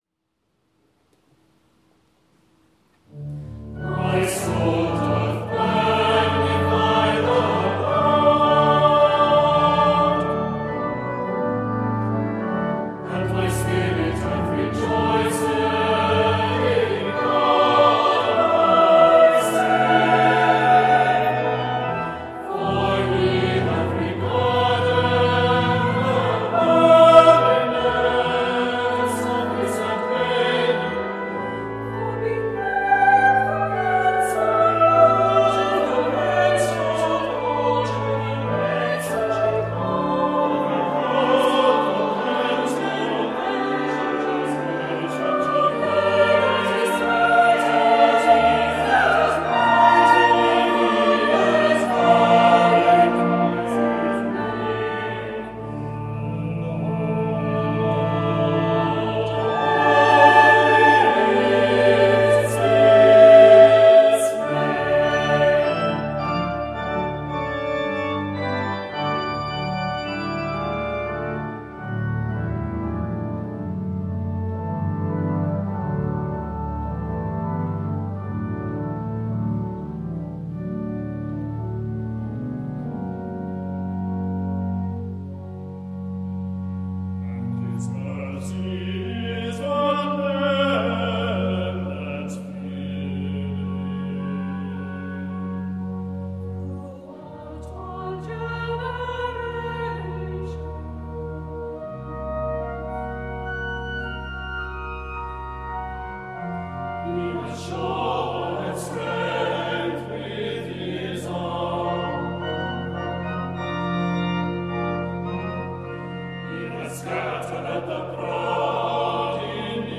Dyson Magnificat in D, sung by the Priory Singers of Belfast at Truro Cathedral